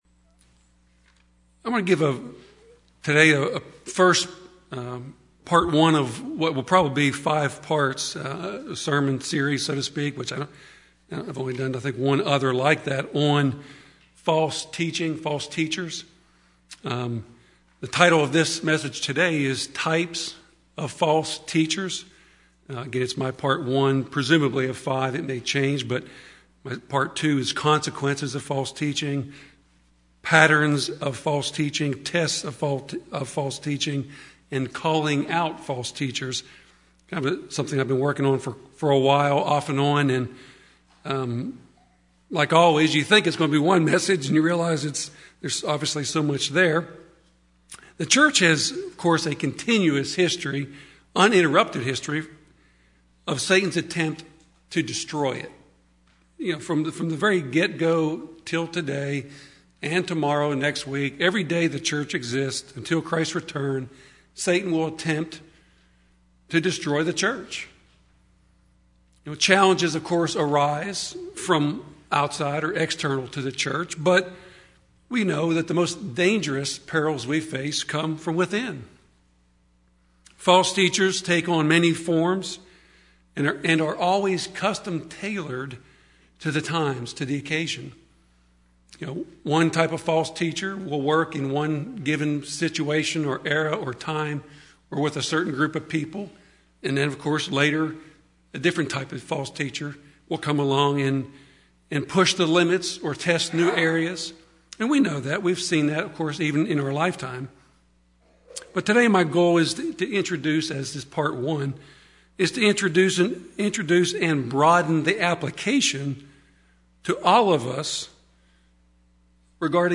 The first of a series of sermons examining the biblical passages on false teachers. This message delves into passages that describe false teachers to help recognize those patterns today.
Given in Nashville, TN